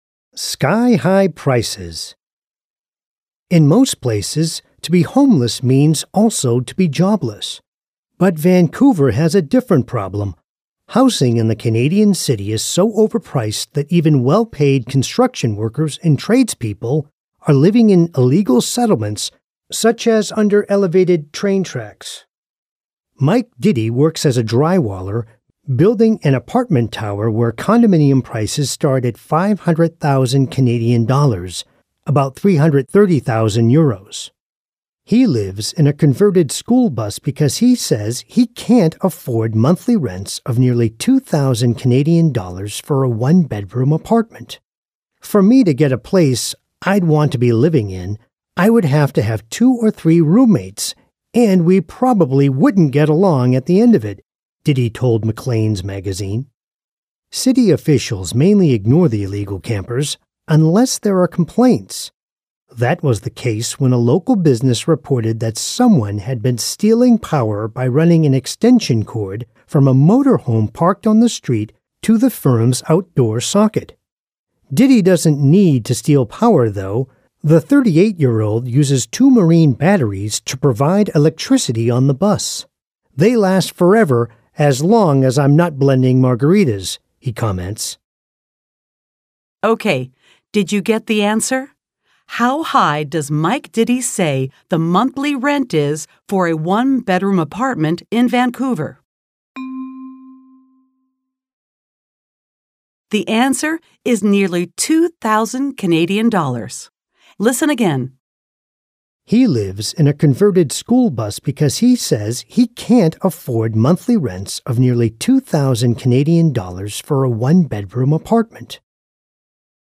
Audio-Übung
Audio-Trainer